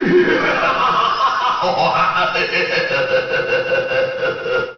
File:Crazy Hand Laugh (Melee).oga
Voice clip from Super Smash Bros. Melee
Crazy_Hand_Laugh_(Melee).oga.mp3